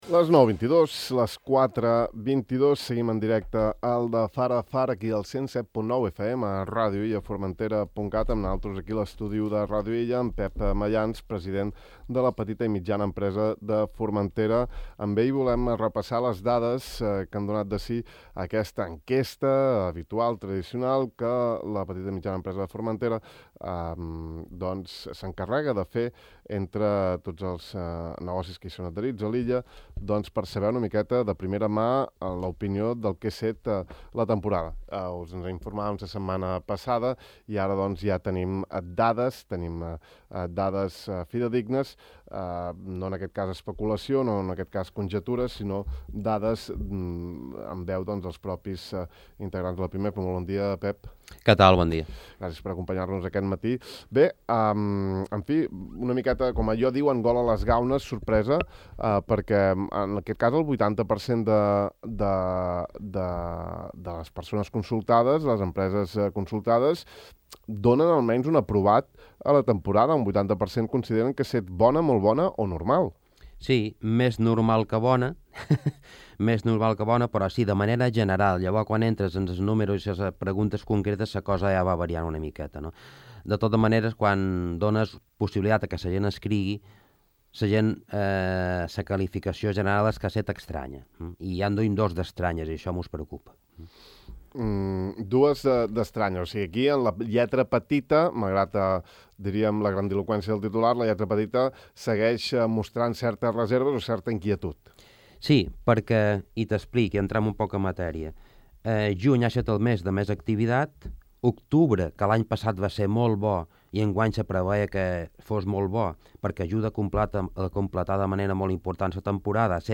entrevista a Ràdio Illa